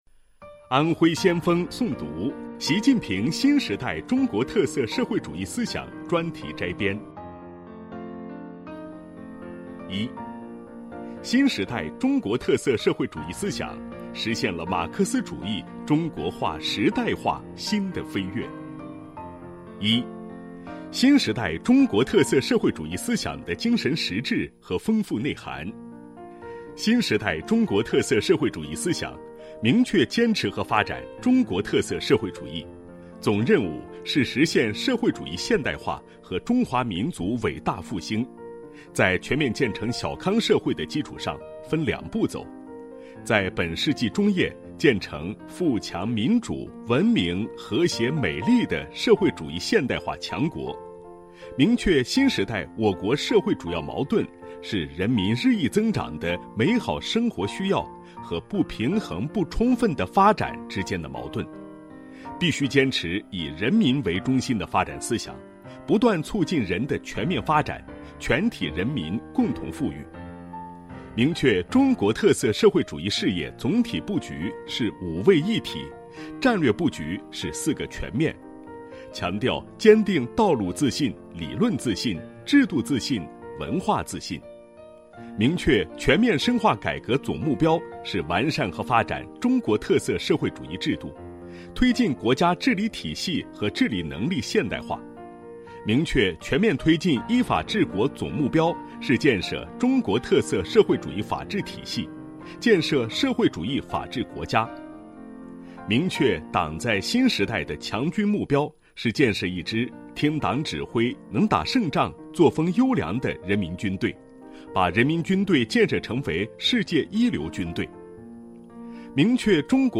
一起读原著学原文悟原理——线上诵读《习近平新时代中国特色社会主义思想专题摘编》①-安徽国元投资有限责任公司